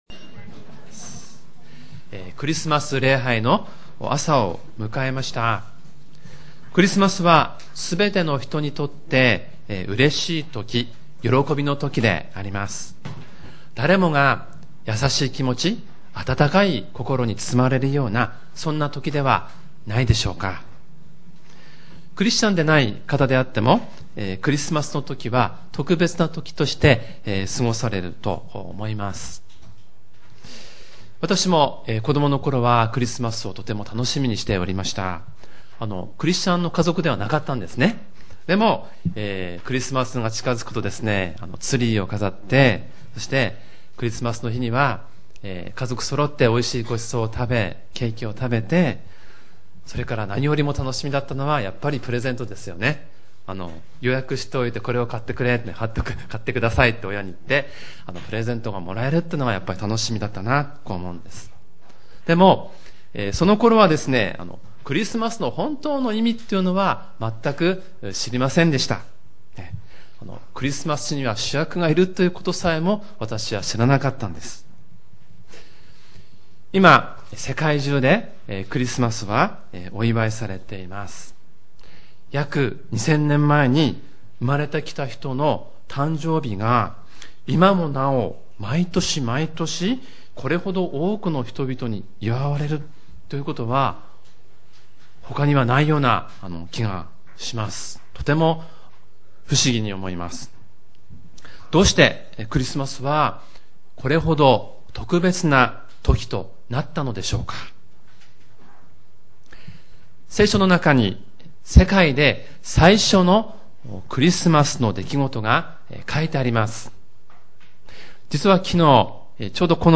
主日礼拝メッセージ